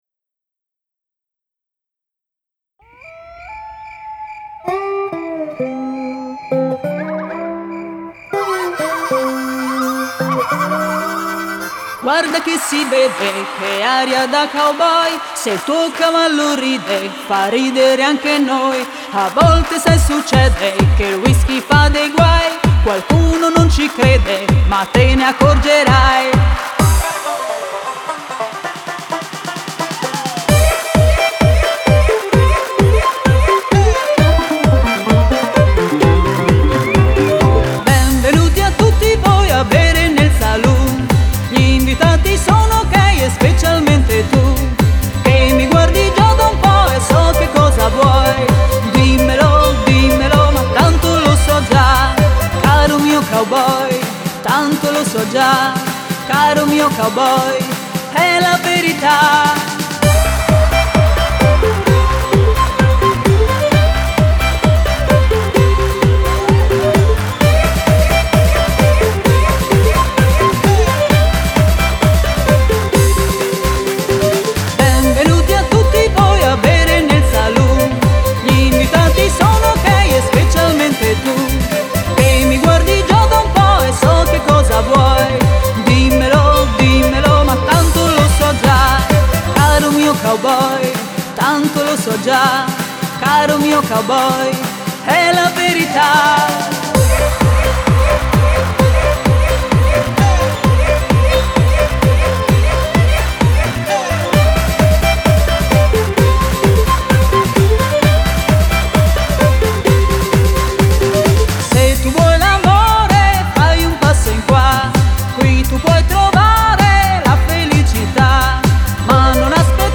New Country Dance